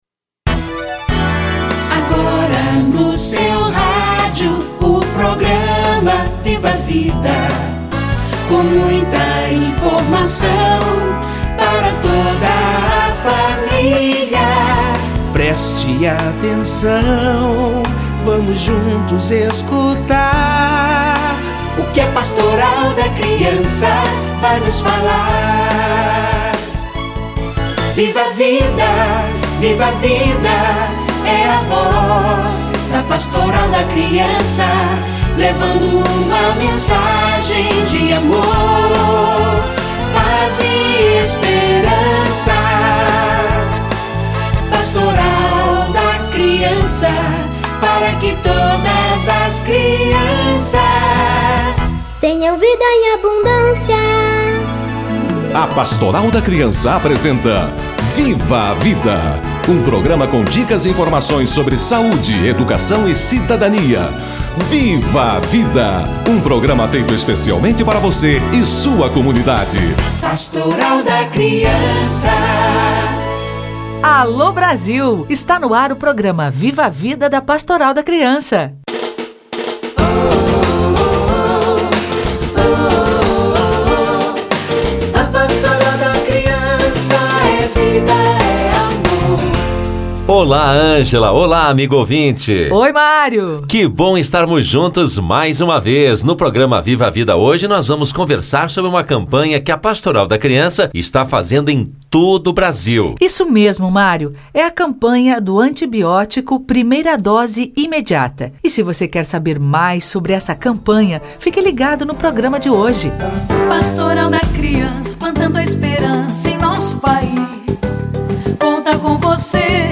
Antibiótico - Entrevista